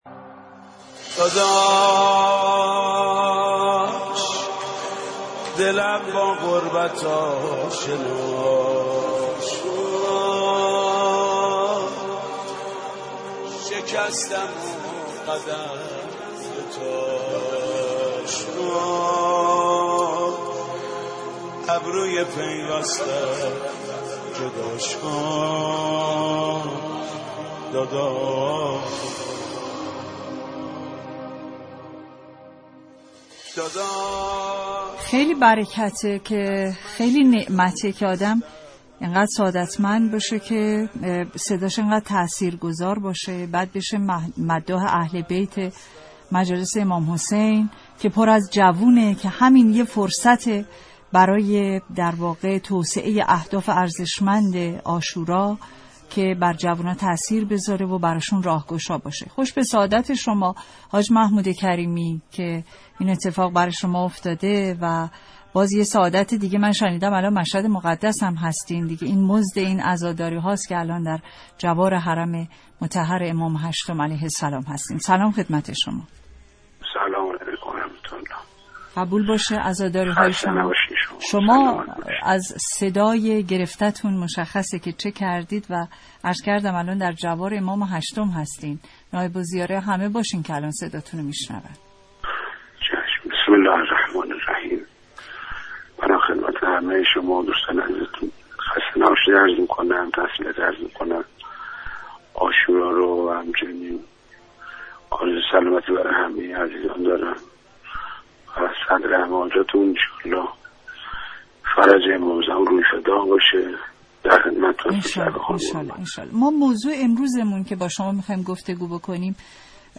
به گزارش گروه "رسانه‌ها" خبرگزاری تسنیم، حاج محمود در گفتگوی با رادیو ایران درباره موضوعات روز اظهاراتی داشته که در ادامه مشروح آن را منتشر میکنیم؛